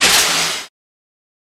Airlock Door Open, Burst Of High Steam With Quickly Fading Hi